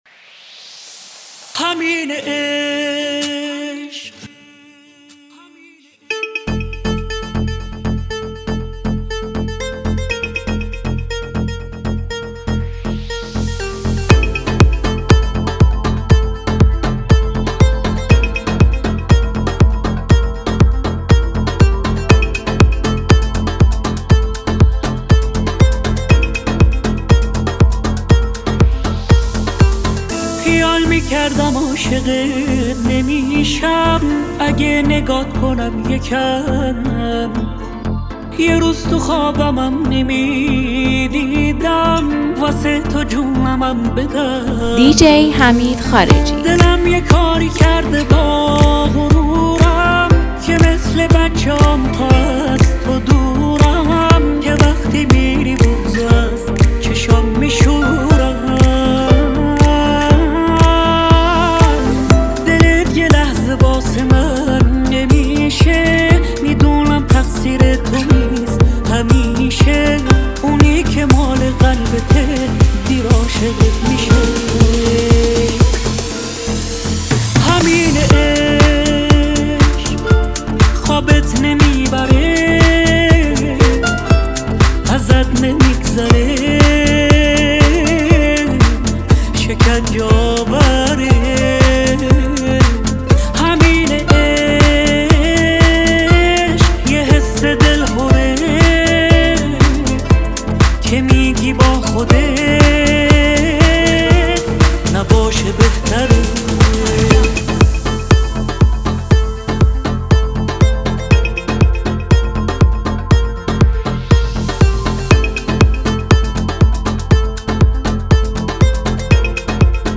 Exclusive Remix